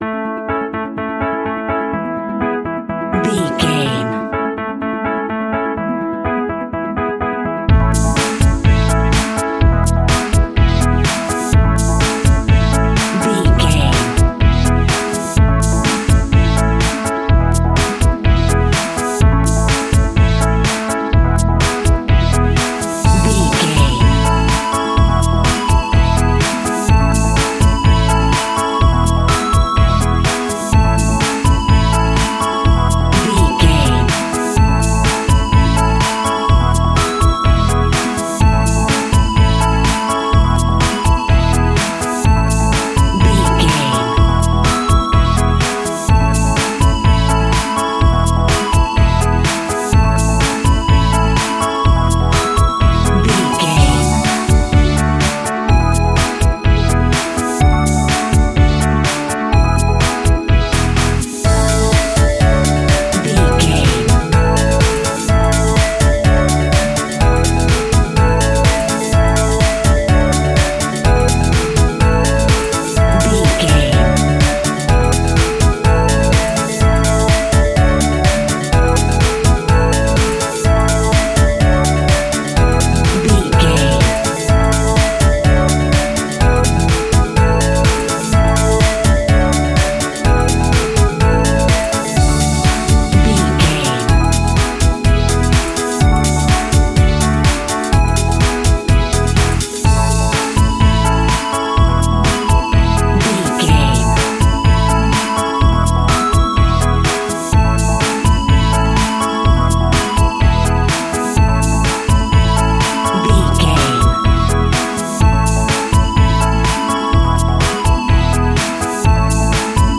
Ionian/Major
pop rock
indie pop
energetic
uplifting
catchy
upbeat
acoustic guitar
electric guitar
drums
piano
organ
bass guitar